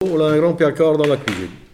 Localisation Saint-Hilaire-des-Loges
Enquête Arexcpo en Vendée
Catégorie Locution